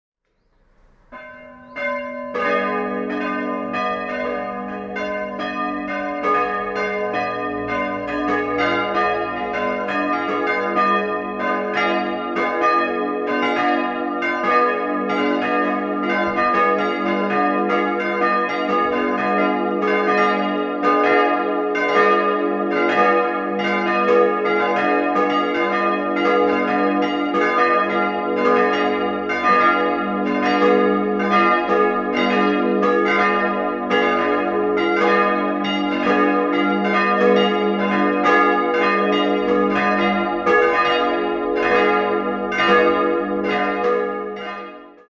Die barocke Ausstattung der alten Kirche wurde nicht übernommen. 4-stimmiges Salve-Regina-Geläute: as'-c''-es''-f'' Alle vier Eisenhartgussglocken stammen von der Firma Ulrich&Weule (Apolda-Bockenem). Die drei kleinen wurden 1921, die große 1929 gegossen.